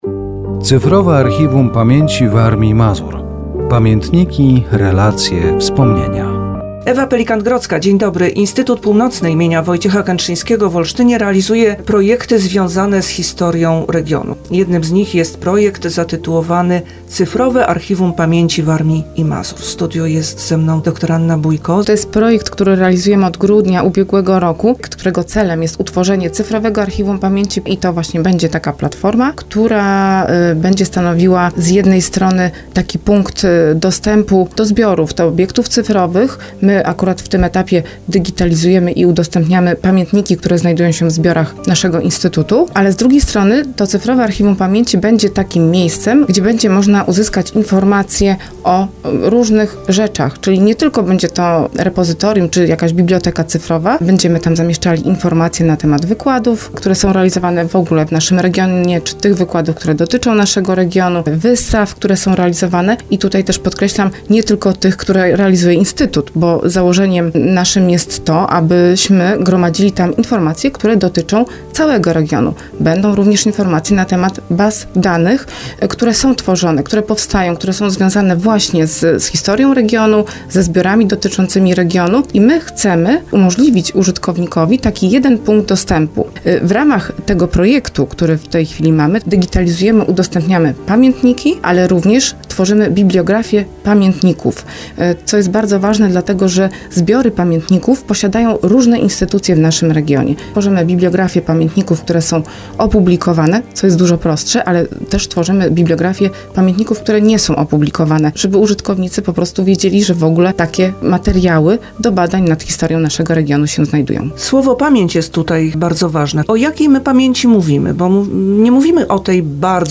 Audycja radiowa poświęcona projektowi "Cyfrowe Archiwum Pamięci Warmii i Mazur. Pamiętniki, relacje, wspomnienia".